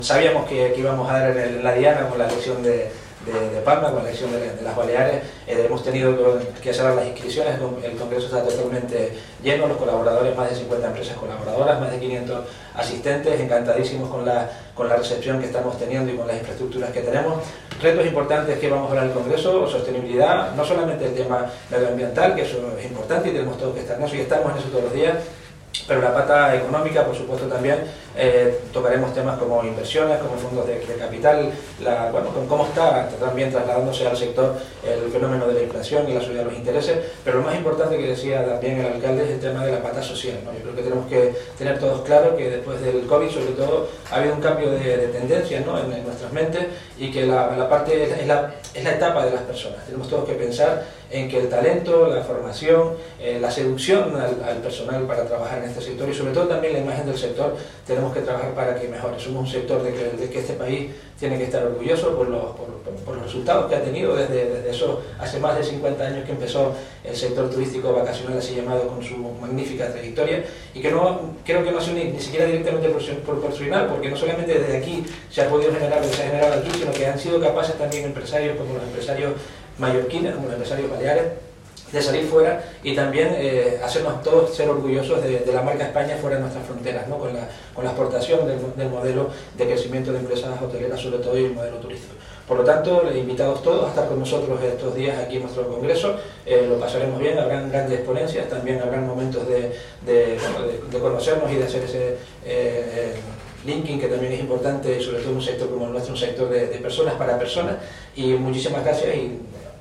22/11: ARRANCA EL CONGRESO CON LA RECEPCIÓN EN EL AYUNTAMIENTO DE PALMA